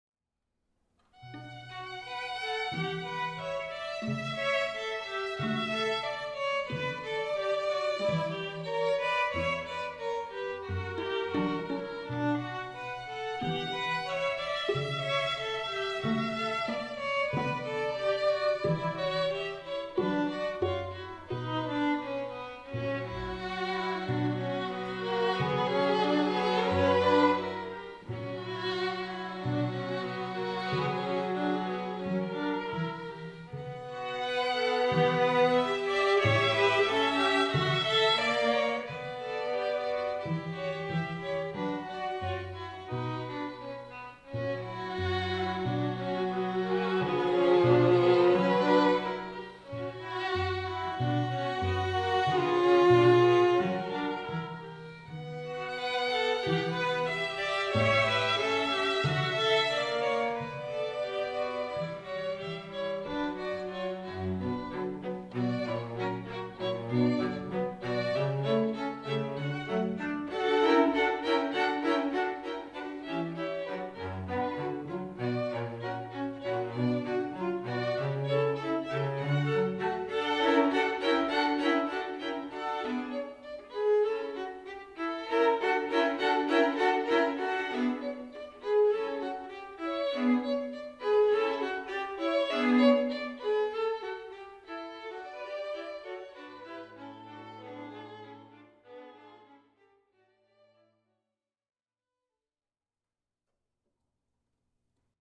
Que ce soit pour rehausser l’élégance et la prestance d’une cérémonie de mariage ou de funérailles; ou encore pour agrémenter et égayer un cocktail, un anniversaire ou un événement corporatif, permettez-moi de vous offrir la plus belle musique pour instruments à cordes en duo, trio ou quatuor accompagnée par d’excellents musiciens professionnels.
Quatuor
Quatuor Op. 44 No2 de Mendelssohn[1:45 min](Musique romantique - Extrait)